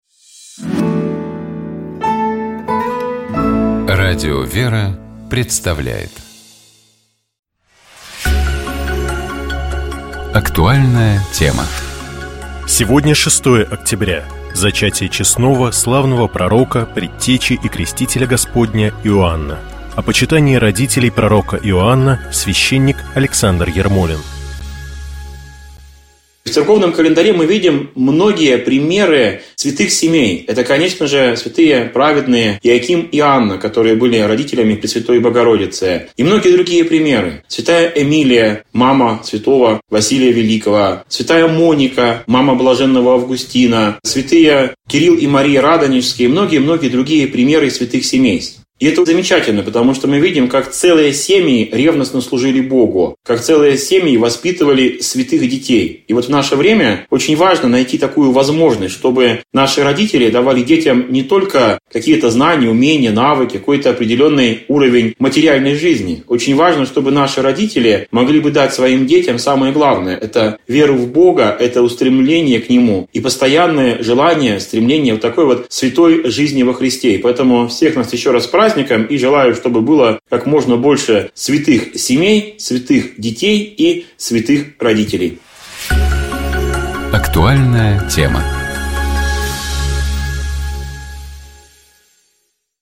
Псалом 70. Богослужебные чтения - Радио ВЕРА